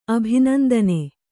♪ abhinandane